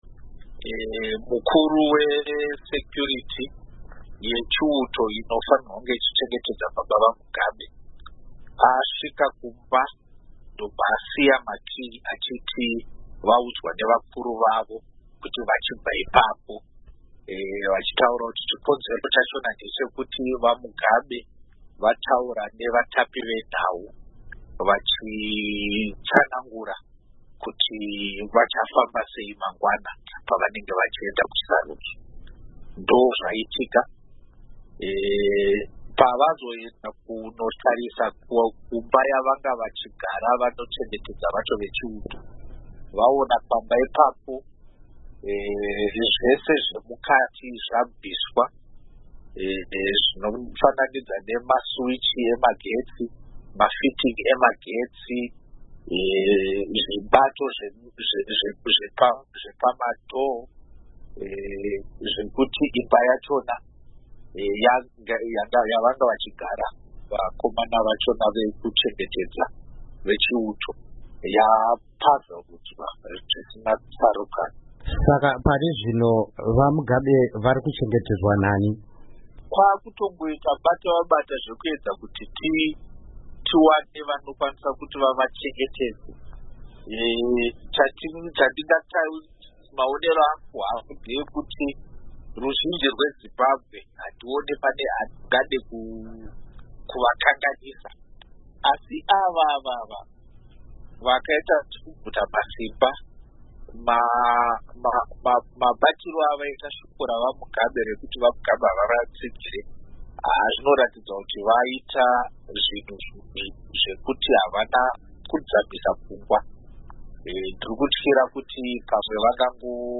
Hurukuro naVaPatrick Zhuwao